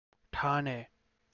ʈʰ
ಠಾಣೆ ṭhāṇe 'station' boathouse